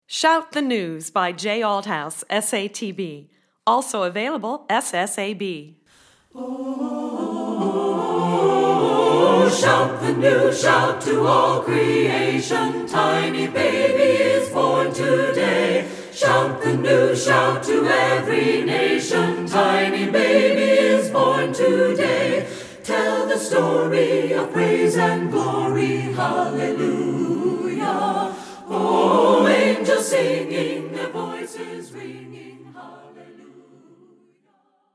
Voicing: SSAB a cappella